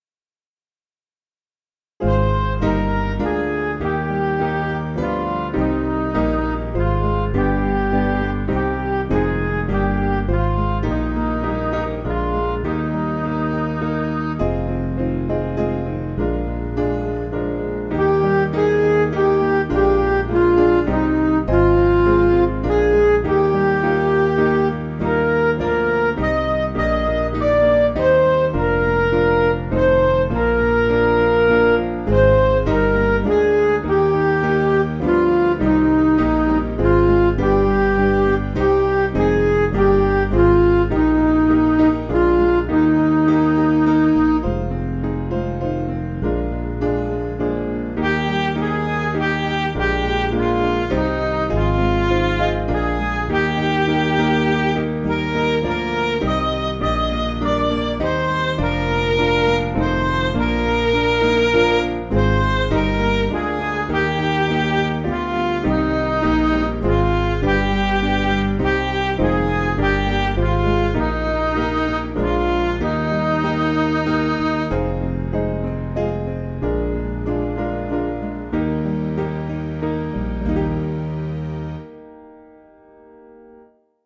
Piano & Instrumental
(CM)   2/Eb